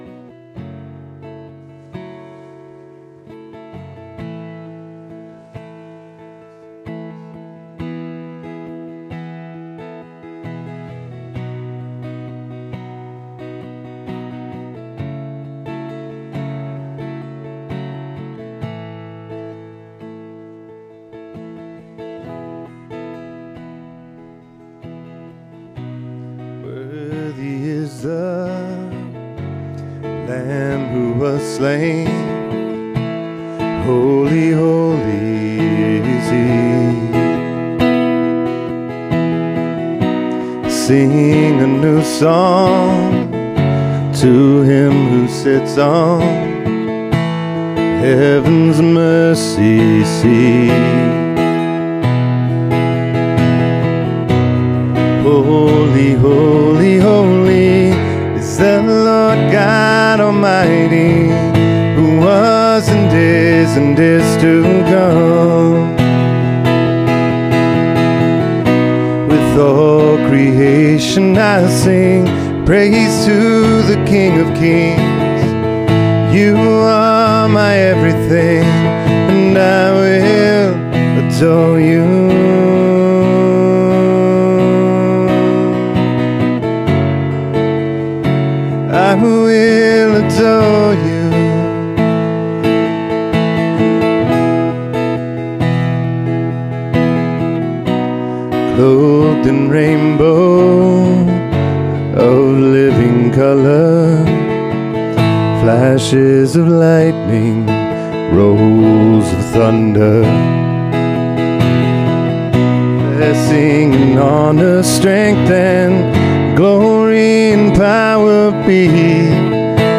SERMON DESCRIPTION Despite his blindness and the crowd’s discouragement, Bartimaeus called out to Jesus in faith—and was heard, healed, and called to follow.